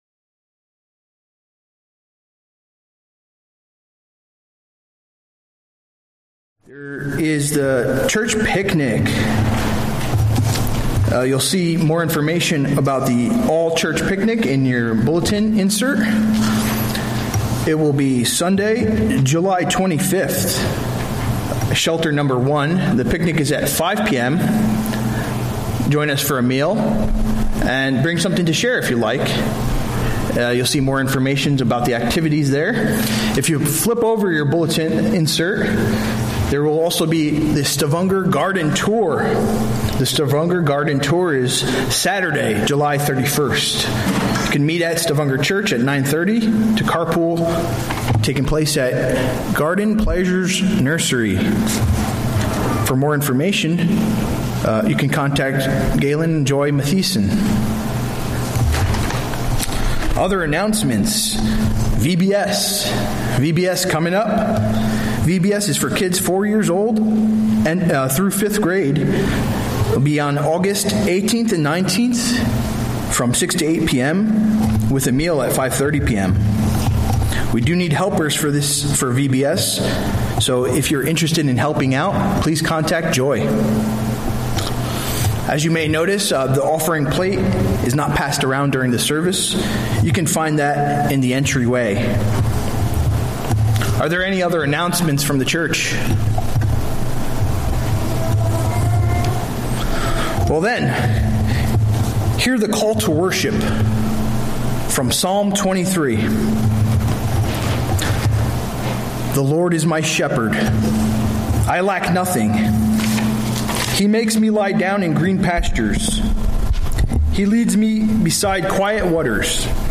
A message from the series "Sunday Worship."